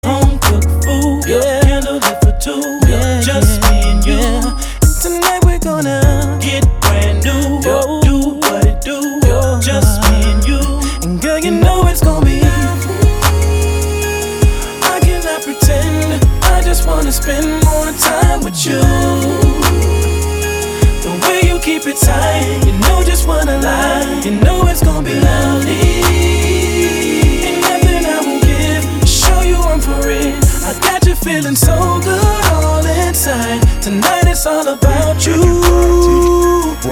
韩国美少女唱的歌曲 非常有意思的小调调